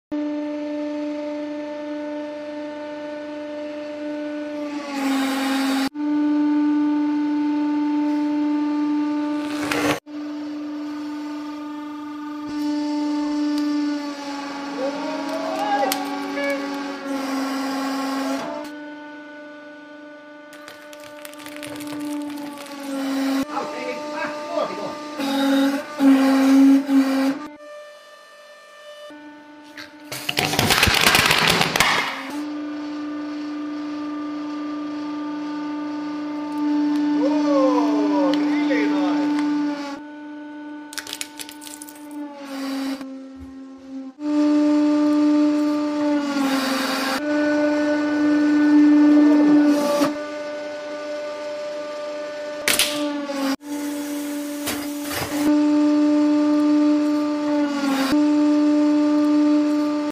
Best Hydraulic Press, See More Sound Effects Free Download